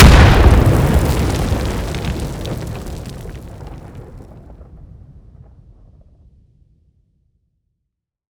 at4rpg_detonate_03.wav